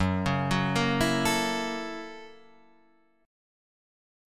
Gb7#9 chord